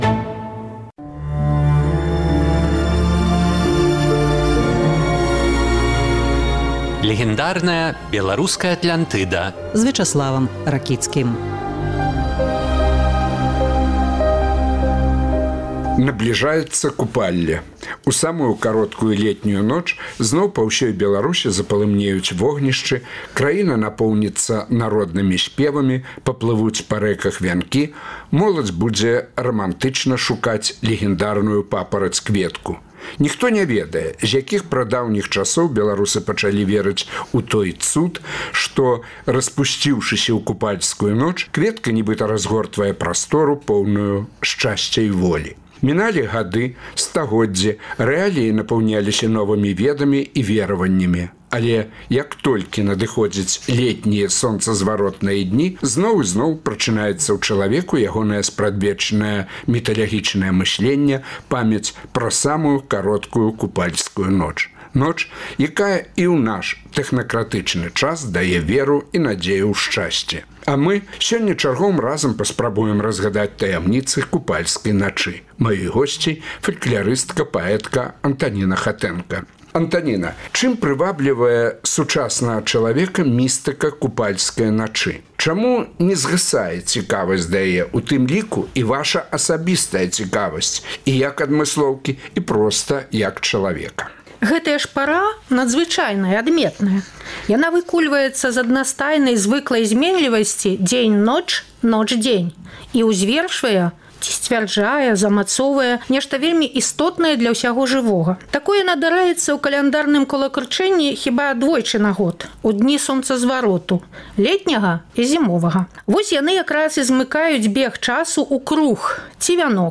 Два месяцы таму ён даў Свабодзе сваё апошняе інтэрвію.